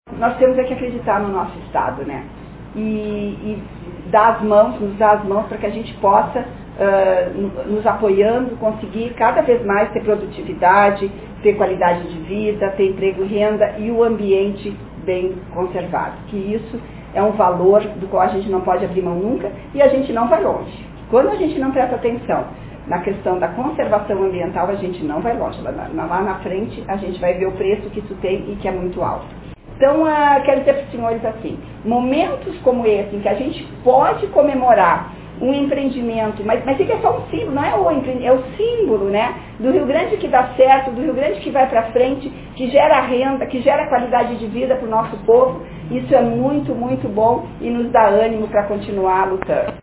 A Secretária Estadual do Ambiente e Desenvolvimento Sustentável , Ana Pellini, destaca a importância da conservação ambiental e ressalta a positividade do empreendimento que está sendo realizado: